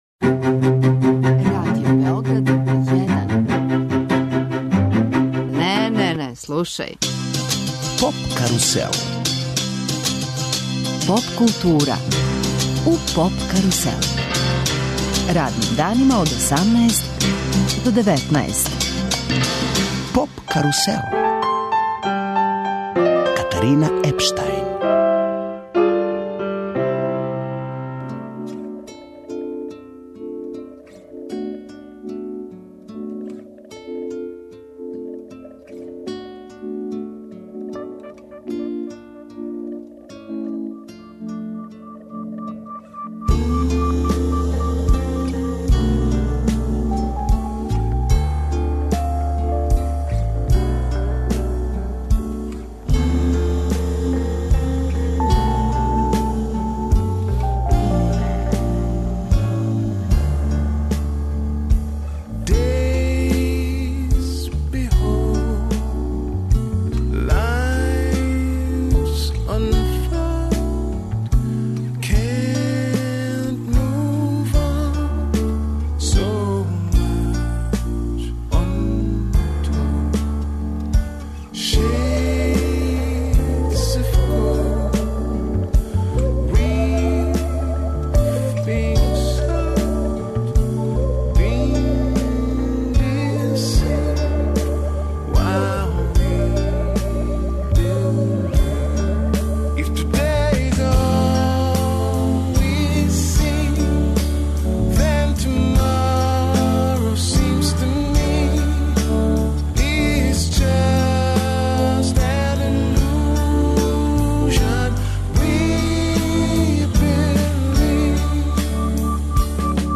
Гости емисије су чланови групе Бркови, који ће најавити предстојеће концерте.